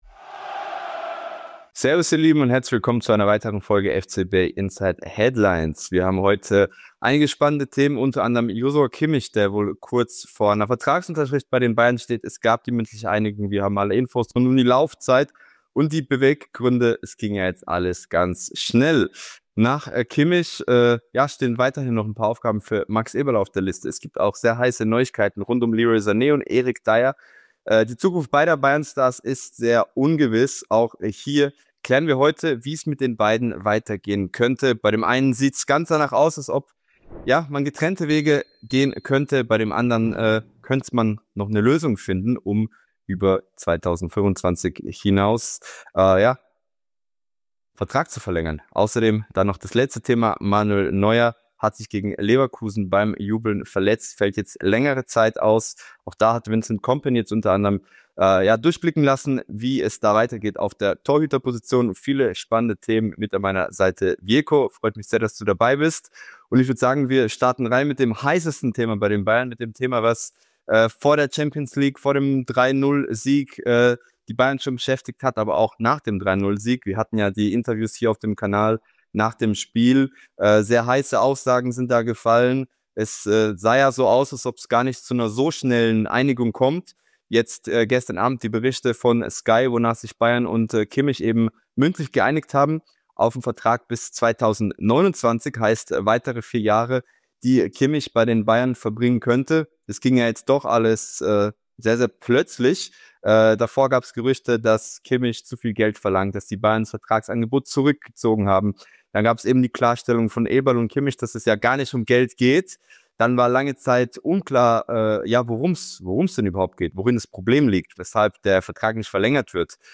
In unserem FC Bayern Podcast unterhalten wir uns einmal im Monat über die aktuellen Geschehnisse beim FCB und werden dabei, gemeinsam mit unseren Gästen, alle spannende Themen rund um den FC Bayern diskutieren und analysieren.